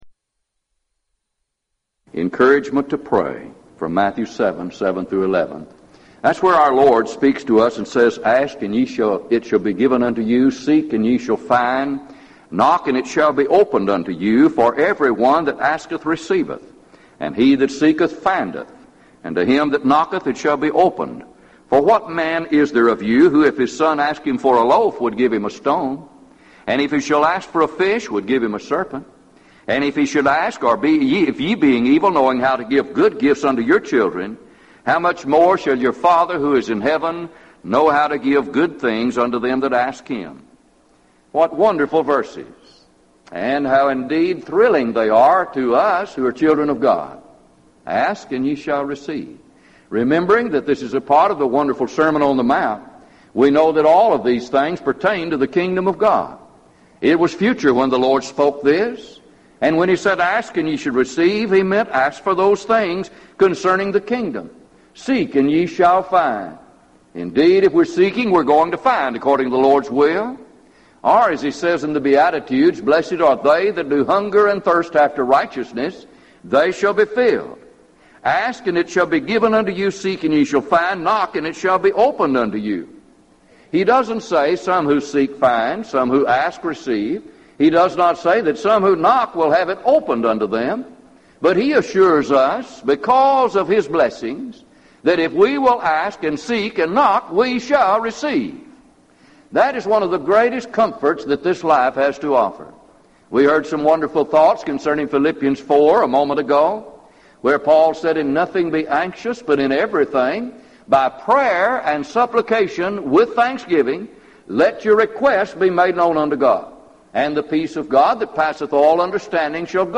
Event: 1998 Gulf Coast Lectures
If you would like to order audio or video copies of this lecture, please contact our office and reference asset: 1998GulfCoast28